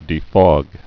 (dē-fôg, -fŏg)